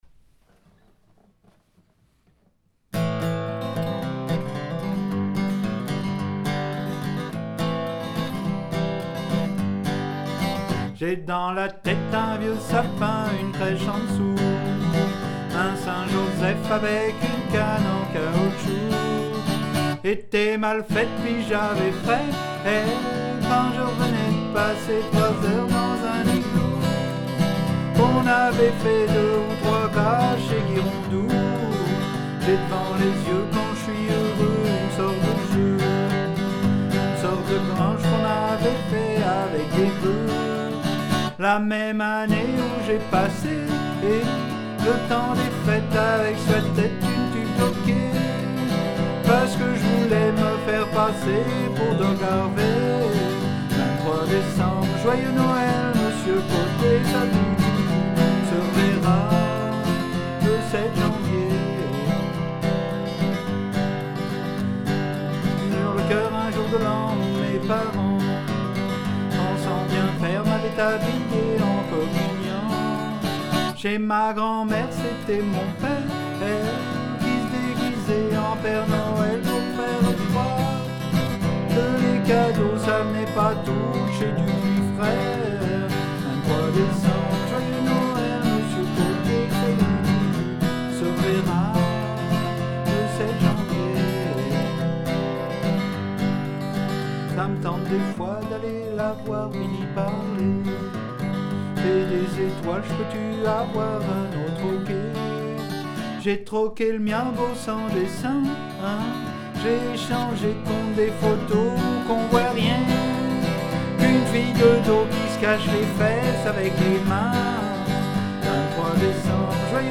guitare n°1 :